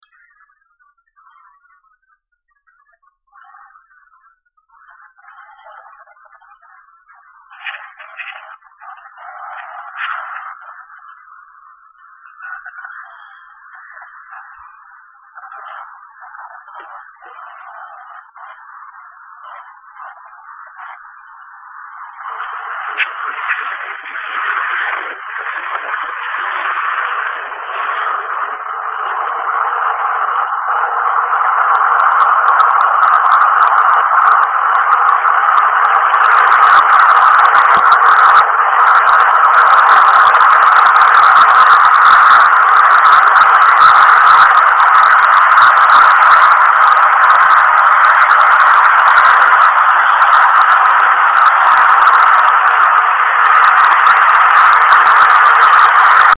One of my first few minutes on the rails. The recording is a bit distorted... but that is somewhat appropriate because there were so many sounds that I suddenly realized I did not know.
The loud sound at the end is a train passing right next to me.